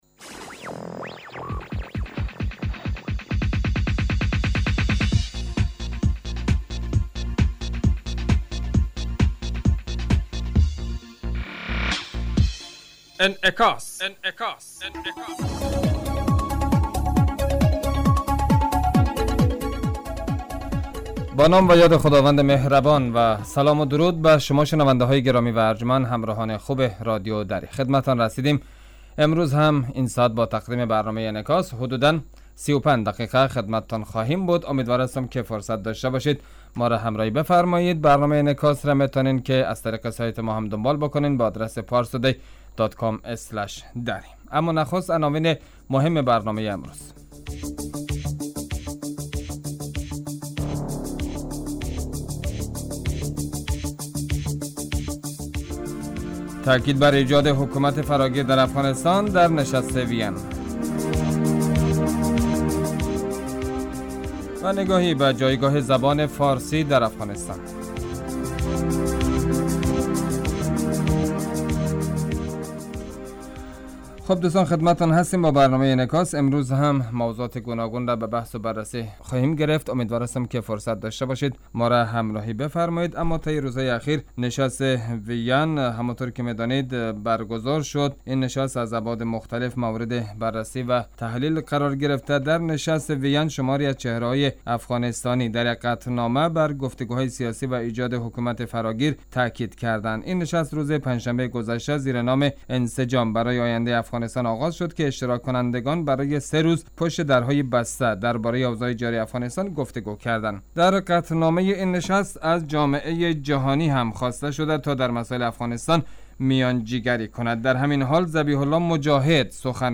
برنامه انعکاس به مدت 35 دقیقه هر روز در ساعت 18:35 بعد ظهر بصورت زنده پخش می شود. این برنامه به انعکاس رویدادهای سیاسی، فرهنگی، اقتصادی و اجتماعی مربوط به افغانستان و تحلیل این رویدادها می پردازد.